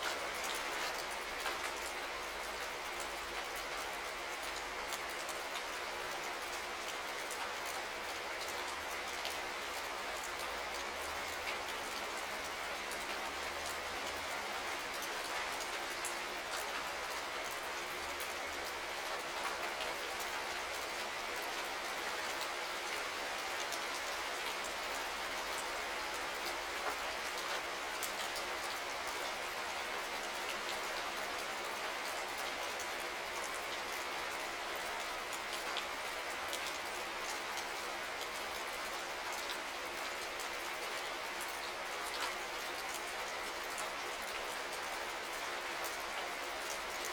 Rain_02.wav